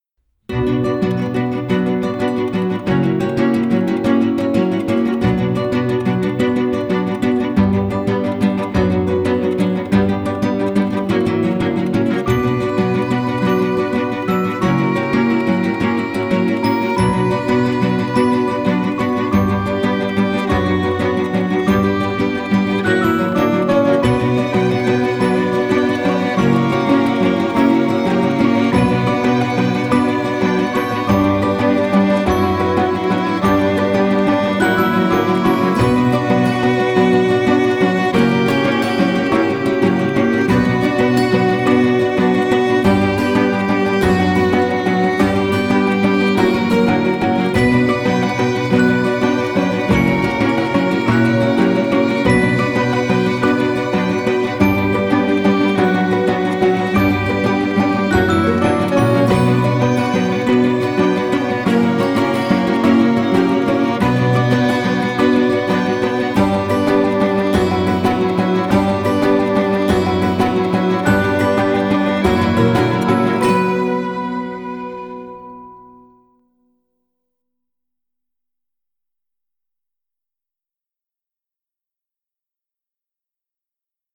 موسیقی متن
موسیقی بیکلام